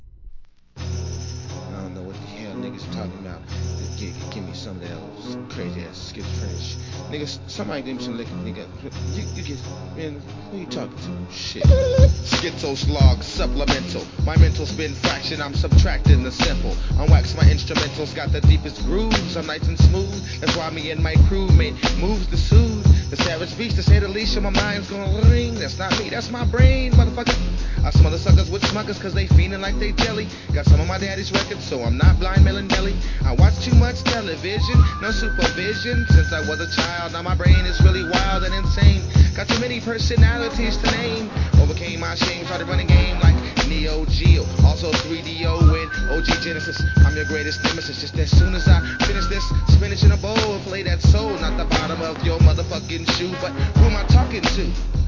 HIP HOP/R&B
真っ黒DOPEなアングラクラシック！！